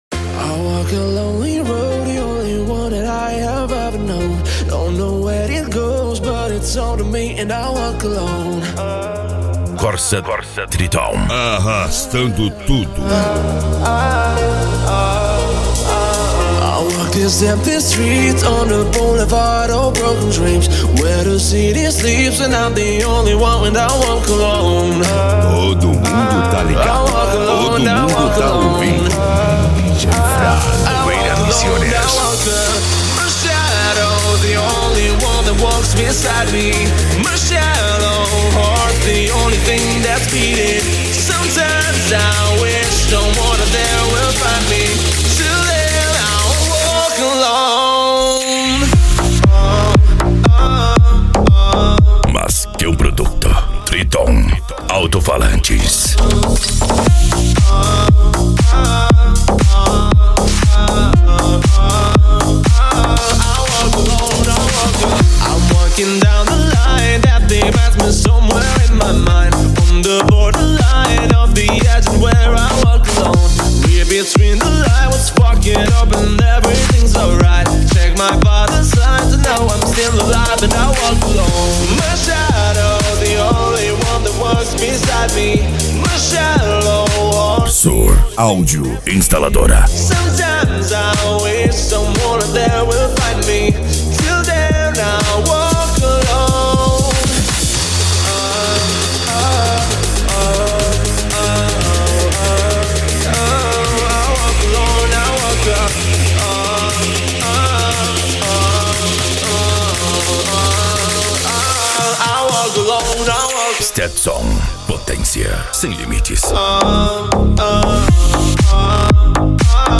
Deep House
Electro House
Psy Trance
Remix